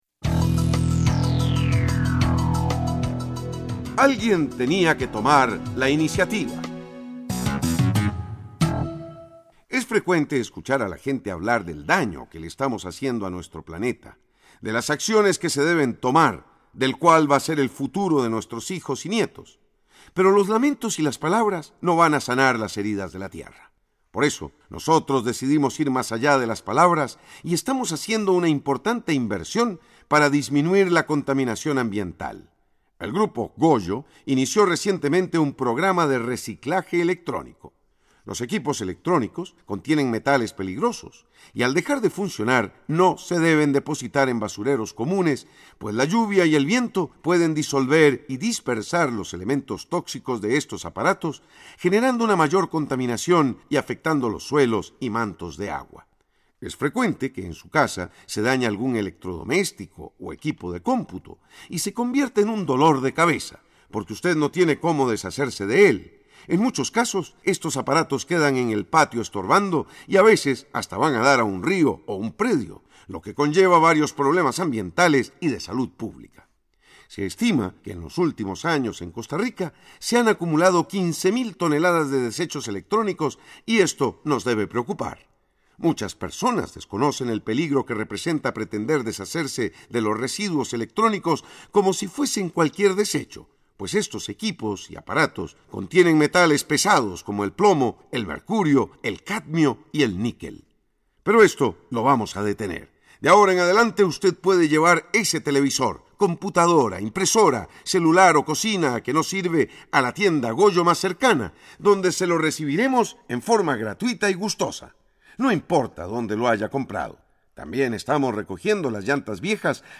Comentarista Invitado